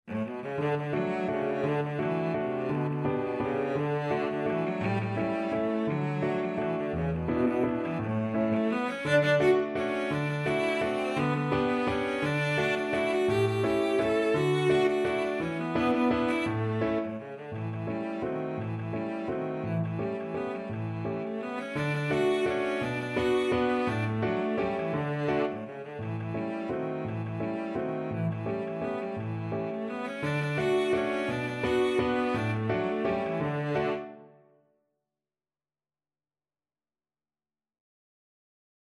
3/4 (View more 3/4 Music)
Classical (View more Classical Cello Music)